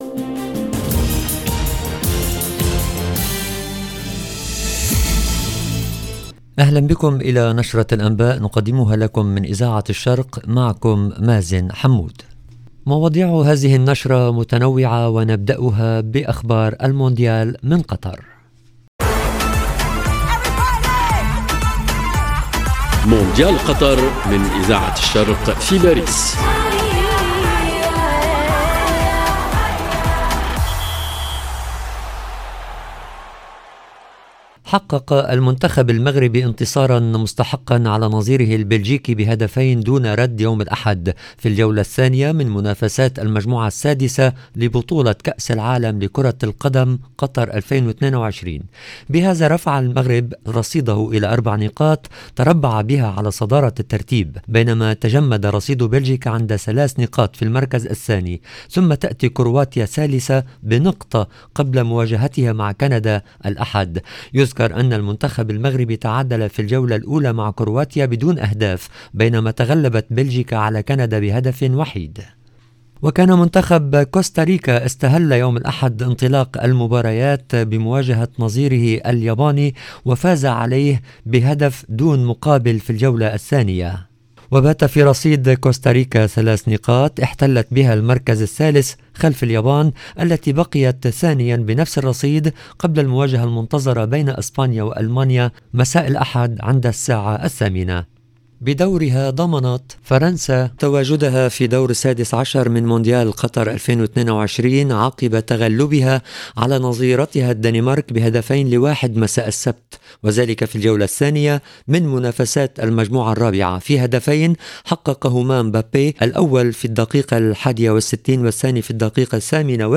EDITION DU JOURNAL DU SOIR EN LANGUE ARABE DU 27/11/2022